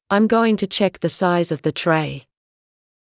ｱｲﾑ ｺﾞｰｲﾝｸﾞ ﾄｩ ﾁｪｯｸ ｻﾞ ｻｲｽﾞ ｵﾌﾞ ｻﾞ ﾄﾚｲ